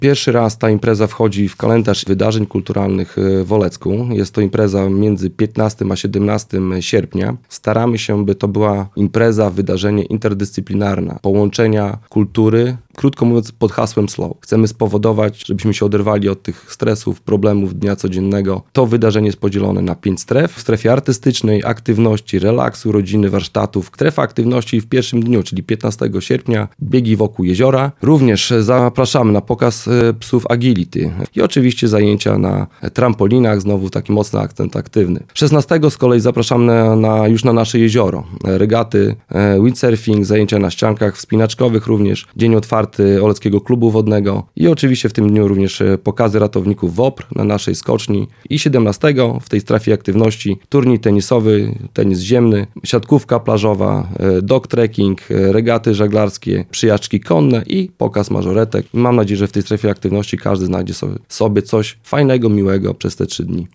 O szczegółach wydarzenia mówi burmistrz Olecka, Karol Sobczak.